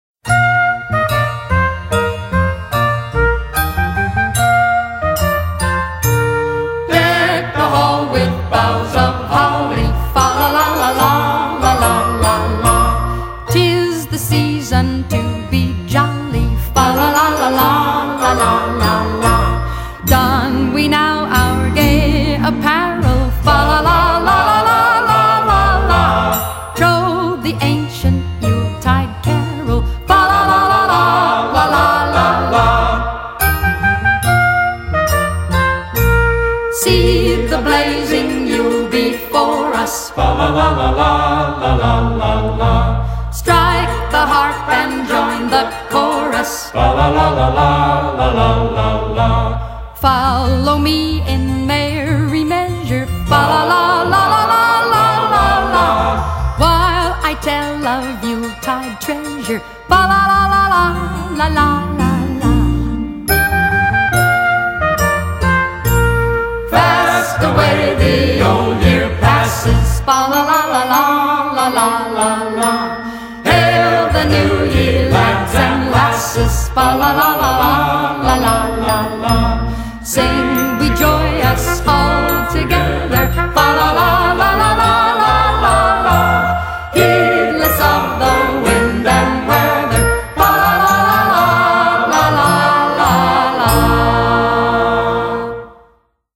类　　别: Pop, Jazz, Christmas　　　　　　　　　　.